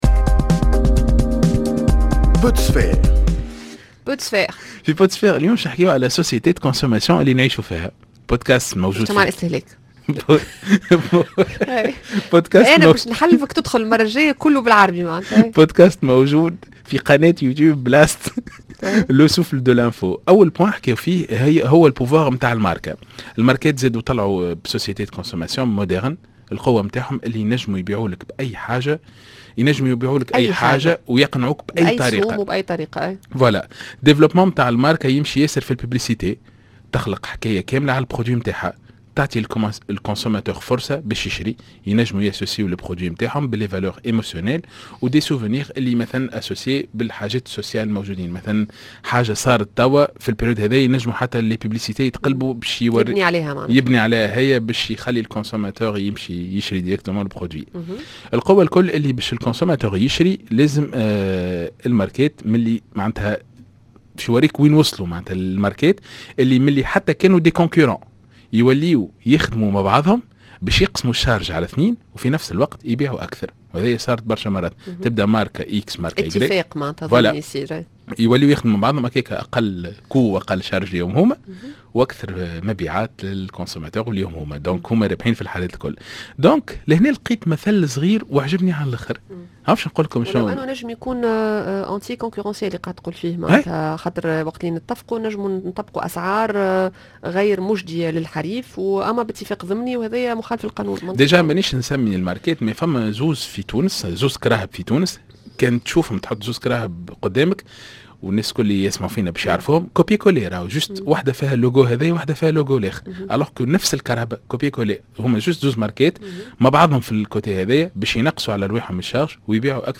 sur les ondes d’Express FM